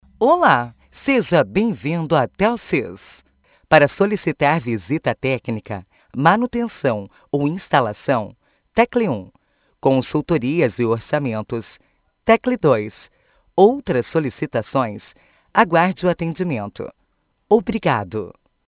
Telsys produz, grava e faz locução do texto de atendimento digital Intelbras personalizado, consulte-nos!
Atendimento Digital Automático Diurno
TELSYS-Atendimento-Diurno.wav